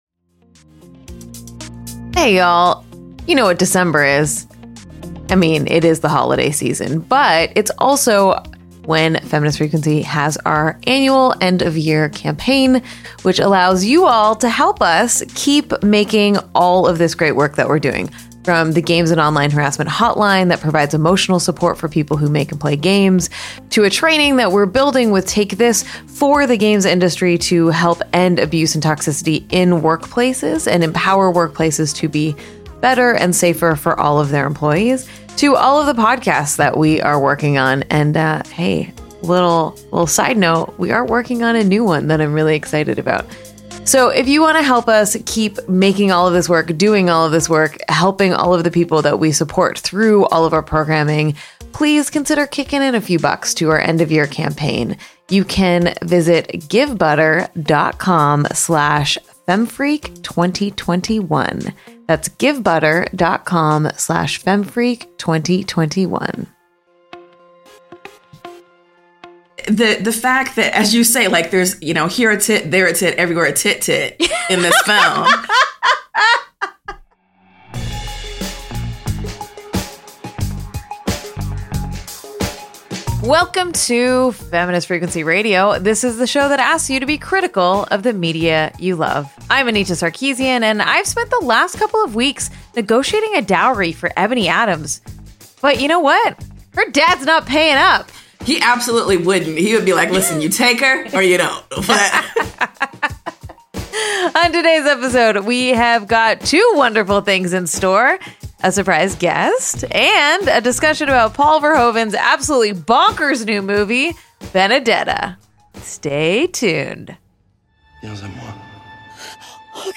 Join us for a lively discourse on this classic political thriller.